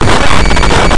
jumpscare.mp3